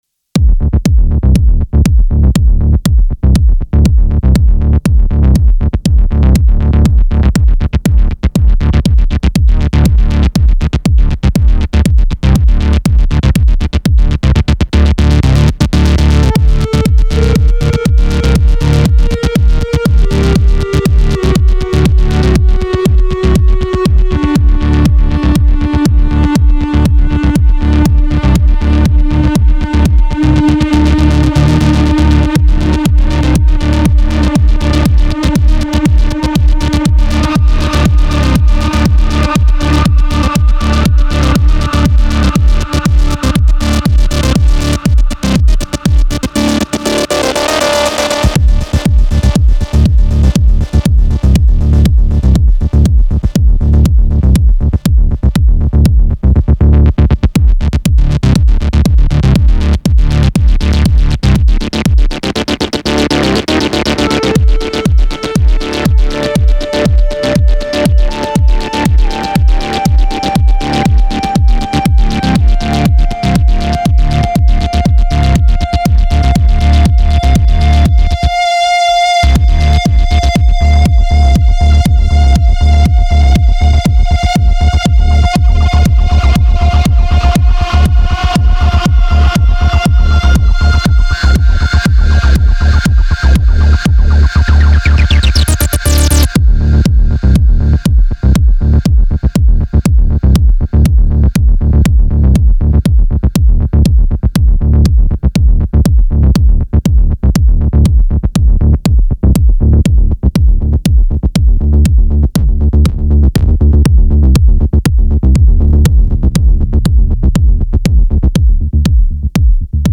I loaded up an old kit and made a 16 step bass and kick pattern, then played the lead synth live while tweaking stuff.